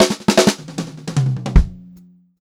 152SPFILL1-R.wav